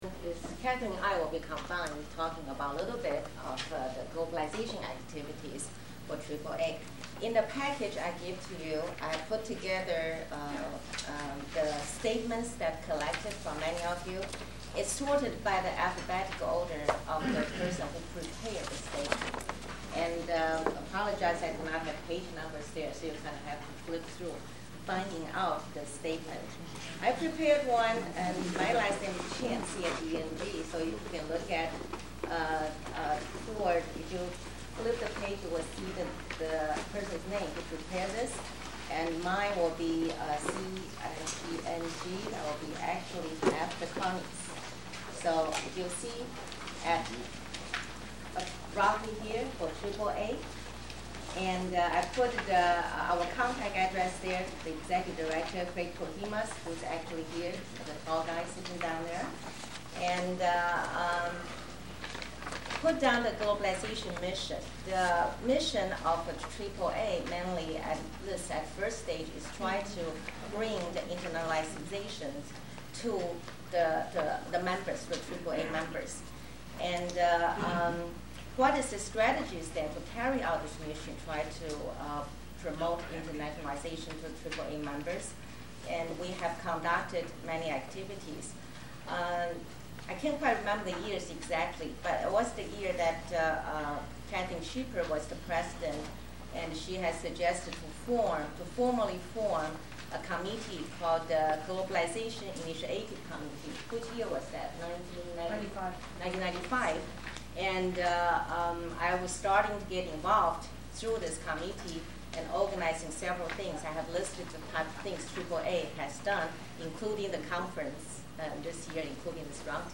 The GSAR speakers in Berlin were not wearing microphones, so the only audio captured was through the microphone built into my old Sony camcorder.